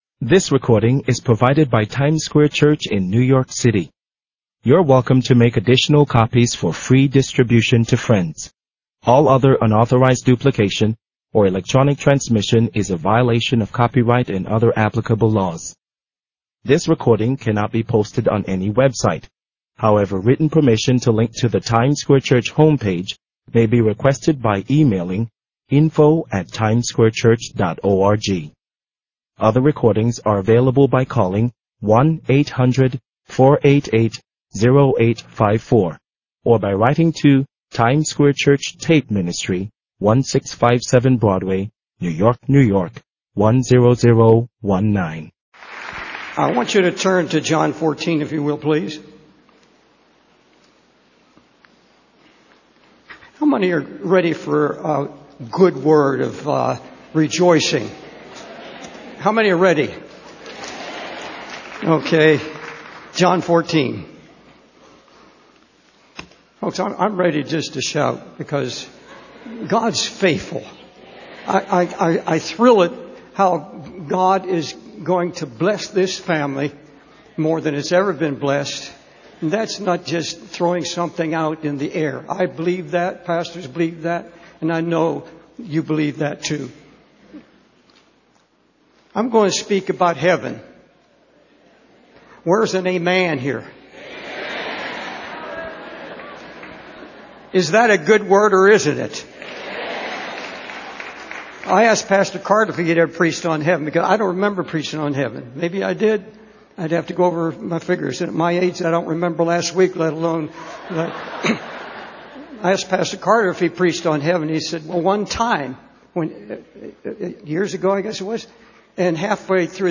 In this sermon, the preacher discusses the concept of heaven and what it will be like. He emphasizes that in heaven, there will be no more death, sorrow, crying, or mourning.